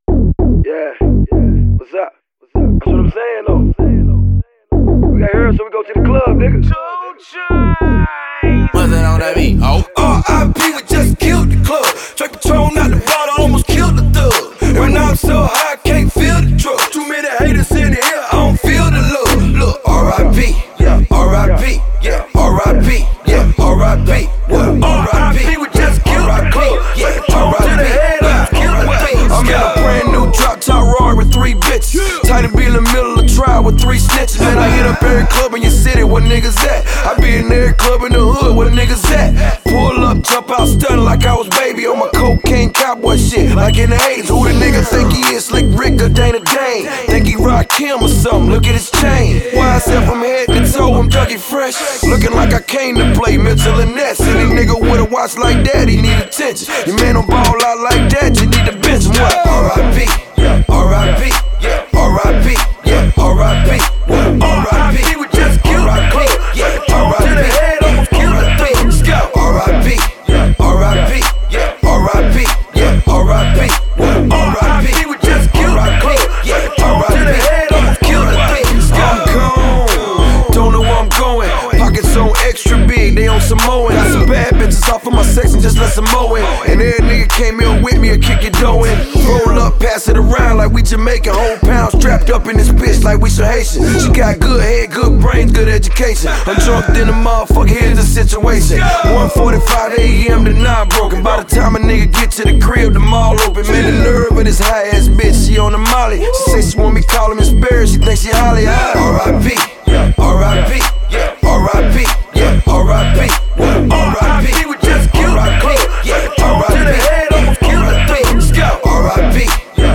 banger